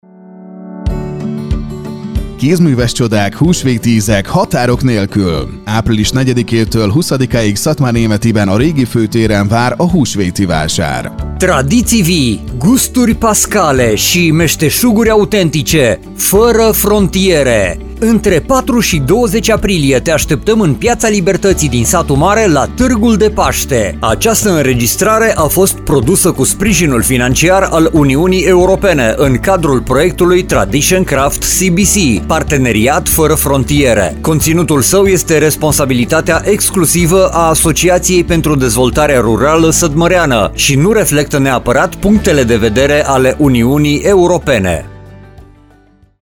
Radio spot - Târg de paște
Targ de Paste - radio spot <